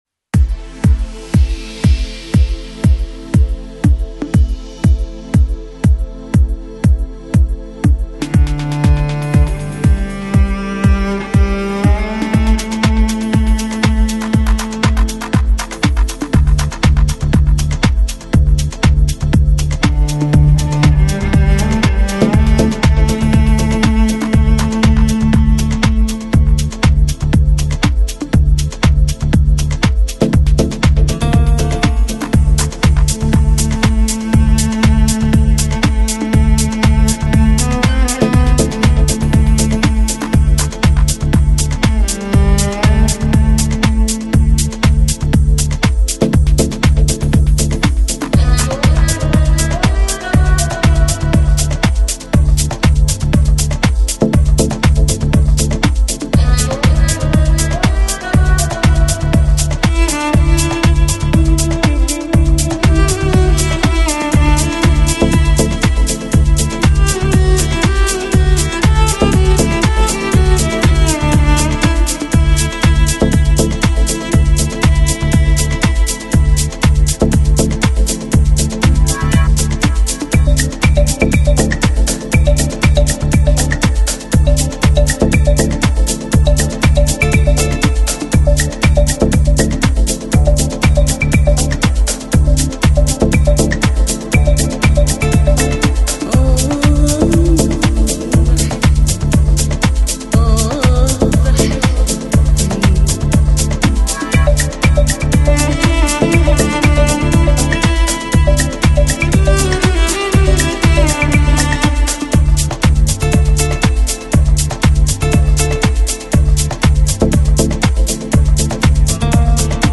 Стиль: Deep House, Organic House